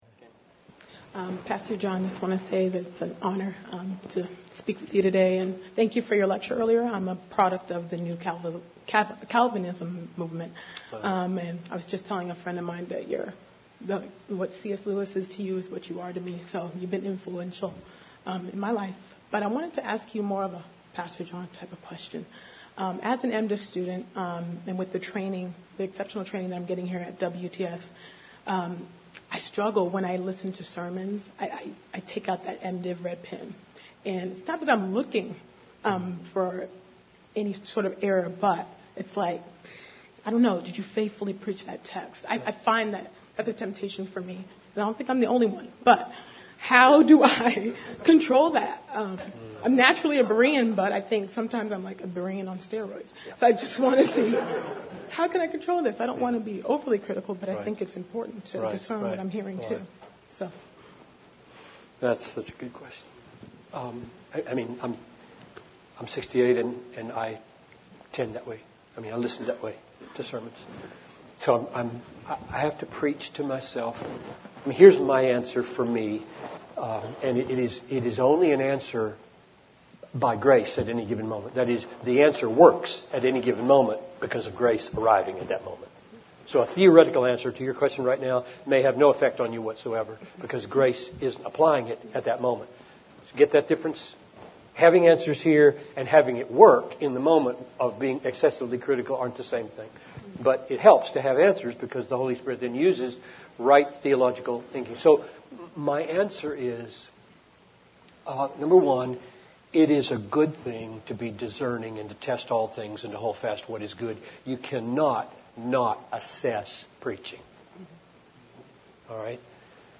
I recently listened to a Q&A session at Westminster Theological Seminary with John Piper where he was asked to speak to this very issue.
Piper-on-Listenting-to-Preaching.mp3